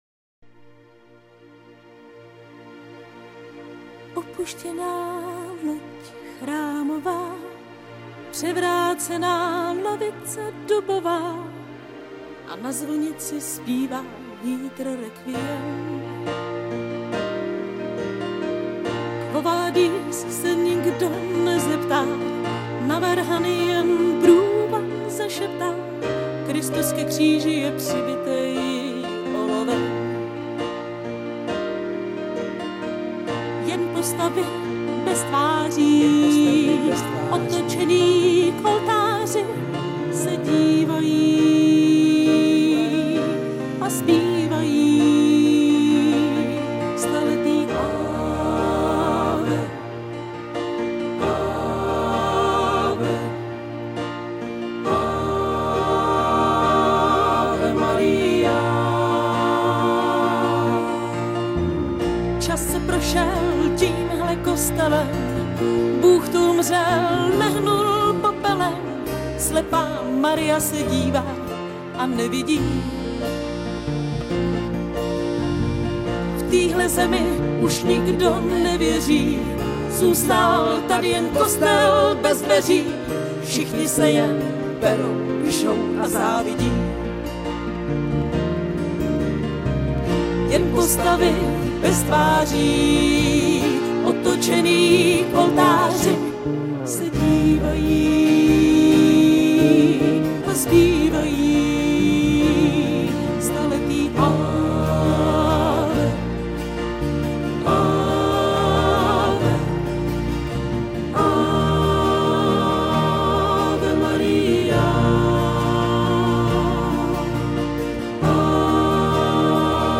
Nejdřív jsem si myslel, že to je nějaký vtip - zapnul jsem to, ozvaly se (asi smyčce?) nástroje a příliš dobrý čistý hlas.